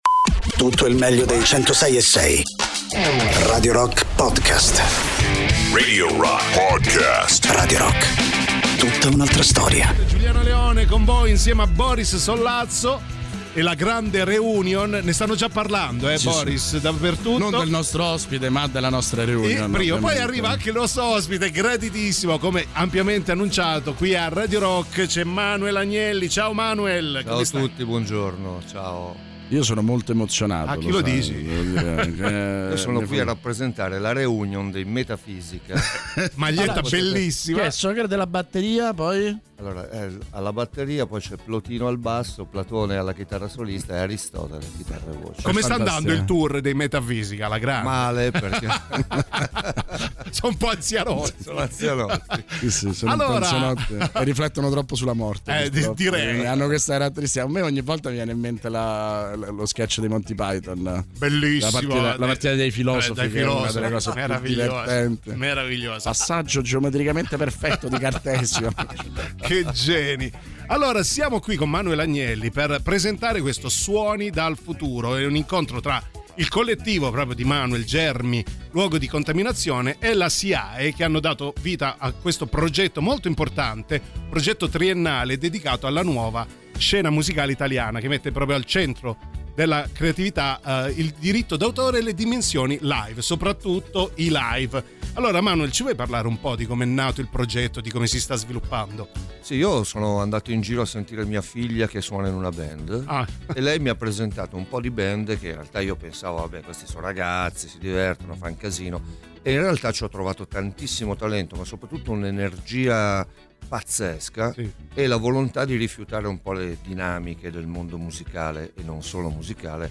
Interviste: Manuel Agnelli (03-04-26)